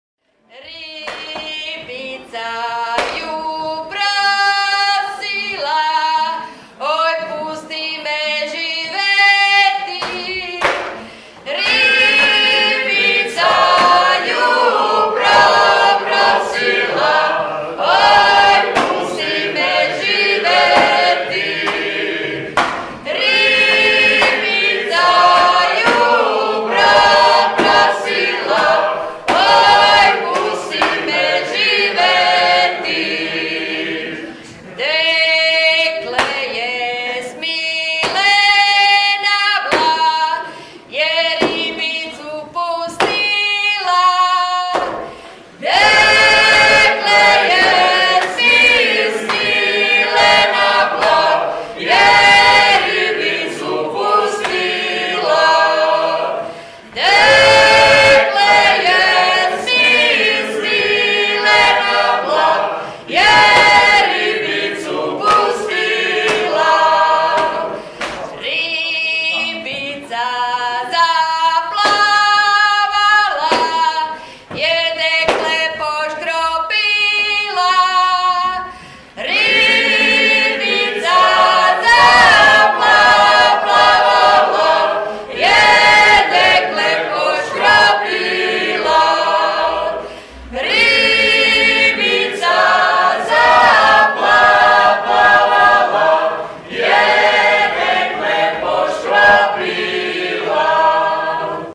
Christmas Show Songs – 2017
5th and 6th Grades – Dekle je po vodo šla